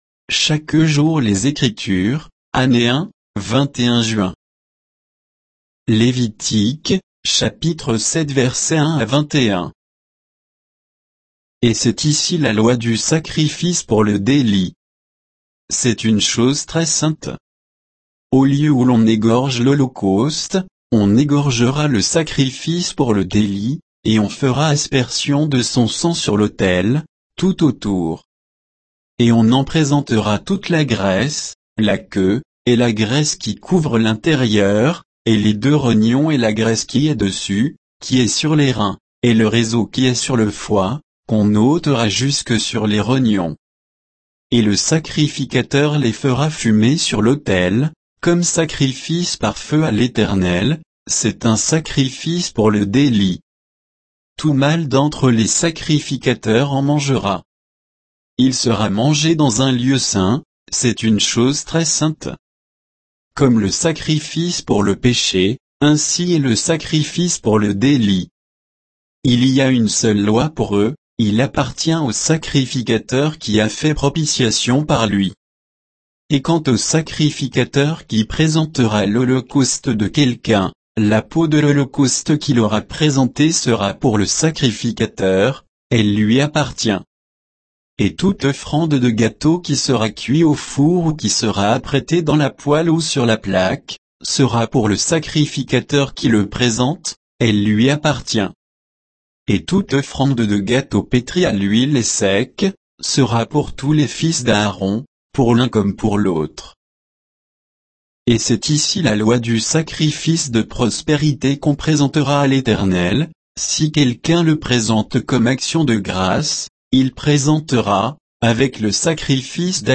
Méditation quoditienne de Chaque jour les Écritures sur Lévitique 7